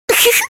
Giggle Sfx - Bouton d'effet sonore